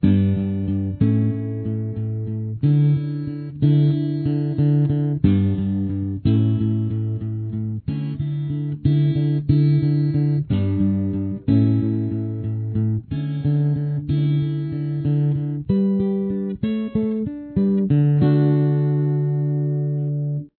D, G, D, G, B, E
The audio is accentuated for timing purposes.
Chorus